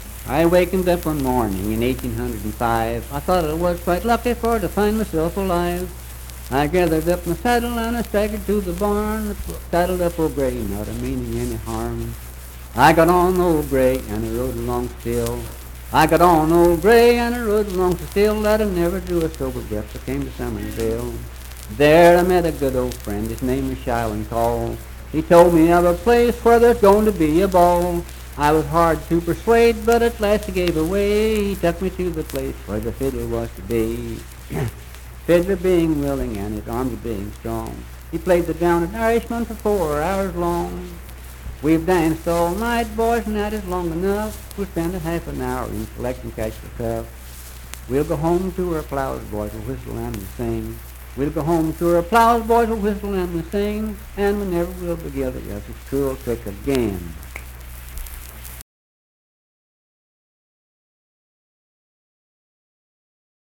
Unaccompanied vocal music performance
Voice (sung)
Calhoun County (W. Va.)